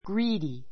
greedy A2 ɡríːdi グ リ ーディ 形容詞 比較級 greedier ɡríːdiə r グ リ ーディア 最上級 greediest ɡríːdiist グ リ ーディエ スト （お金・名誉 めいよ などに対して） 貪欲 どんよく な; 食いしんぼうな, がつがつした He is greedy for fame [success].